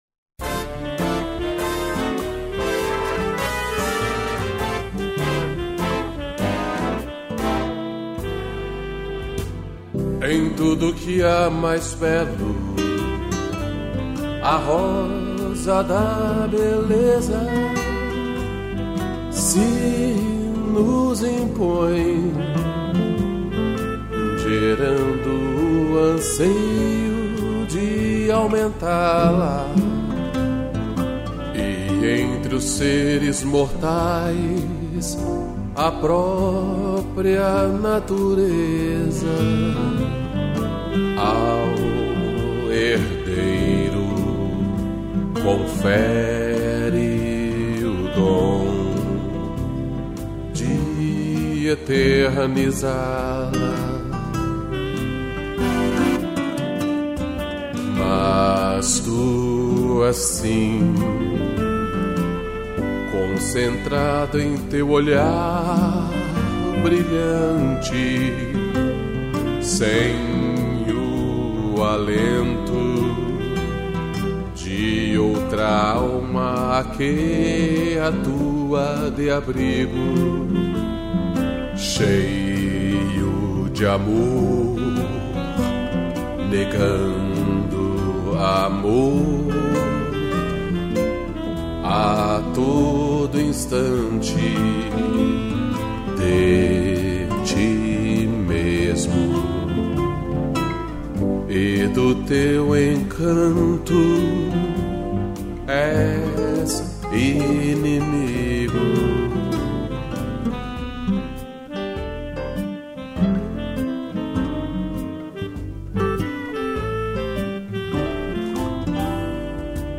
violão e sax